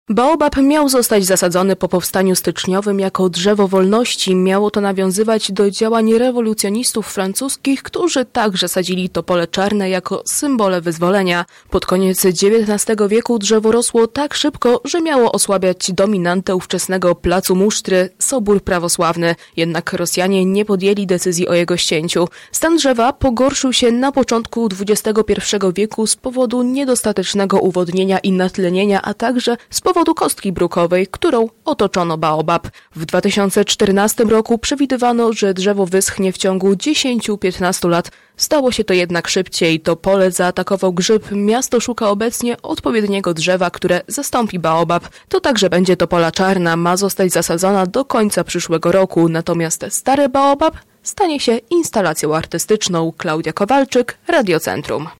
Historię baobabu poznała nasza reporterka: